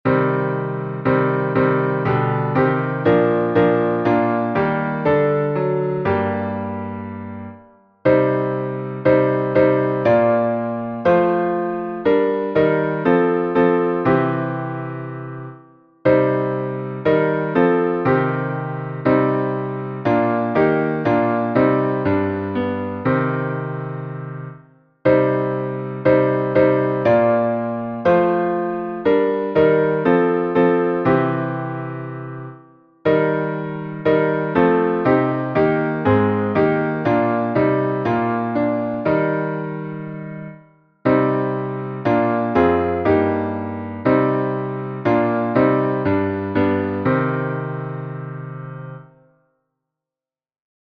Modo: dórico
salmo_37A_instrumental.mp3